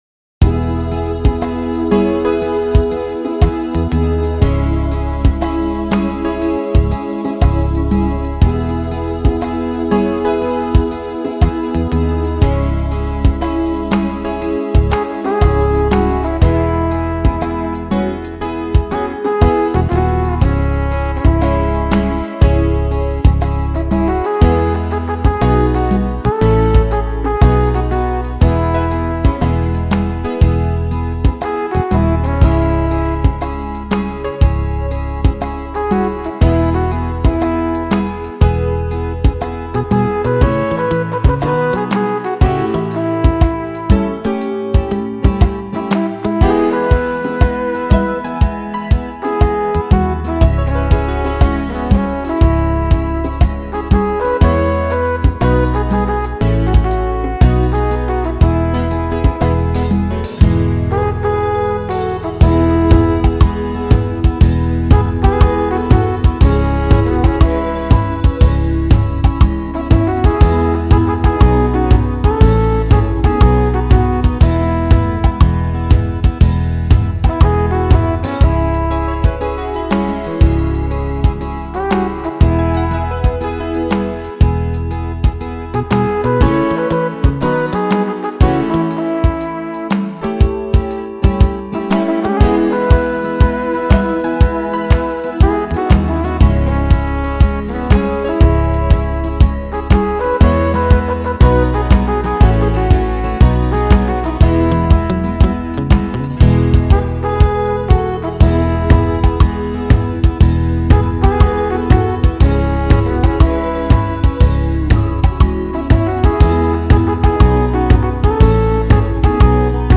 It’s on the Pop/Rock list without the lead line.